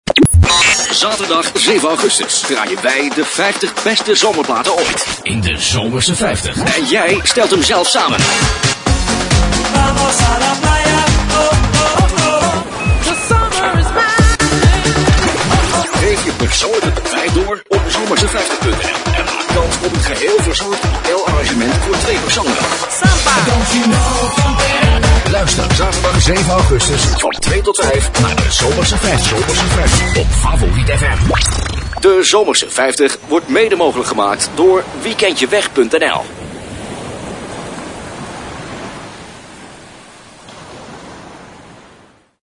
Op deze pagina tref je een aantal voorbeelden van jingles en promo's (in MP3 formaat) aan die vrij recentelijk door mij zijn ingesproken.